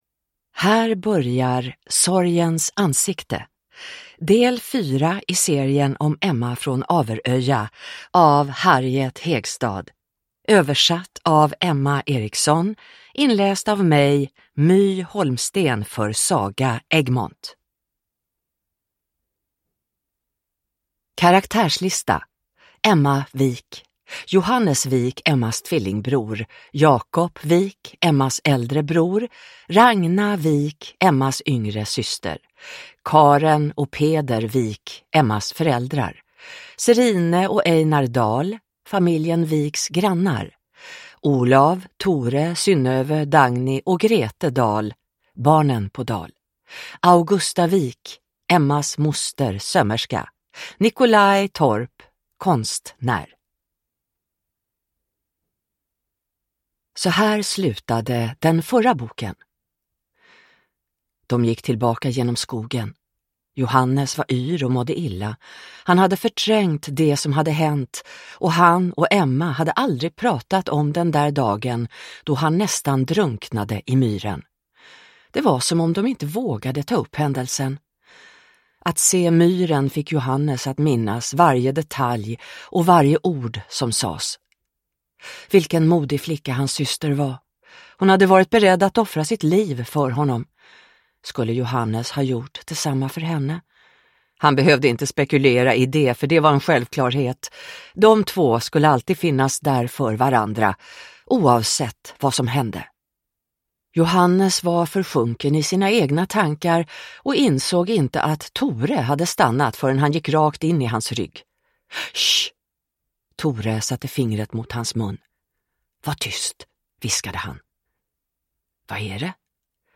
Sorgens ansikte / Ljudbok